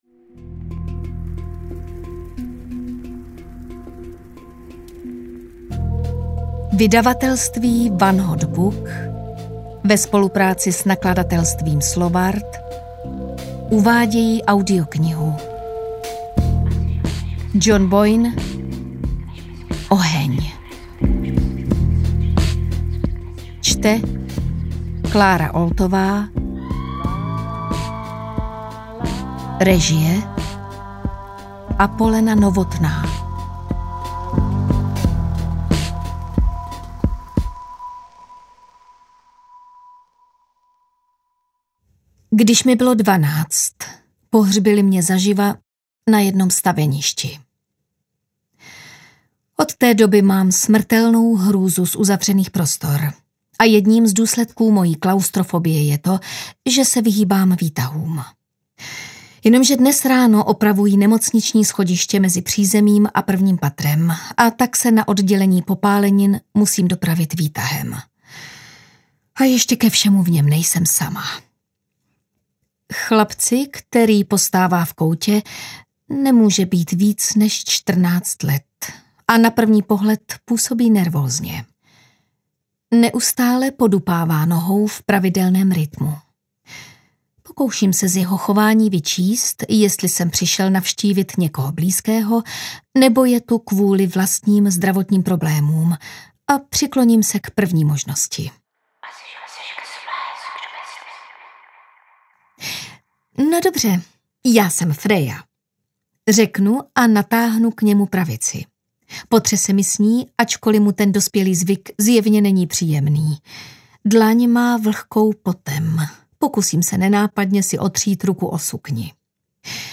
Oheň audiokniha
Ukázka z knihy
• InterpretKlára Oltová